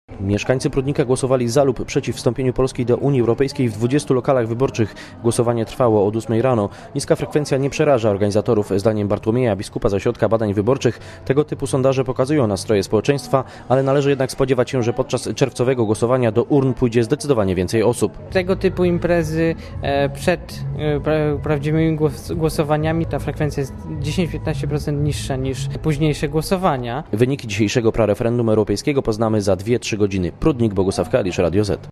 Głosowanie obserwował reporter Radia Zet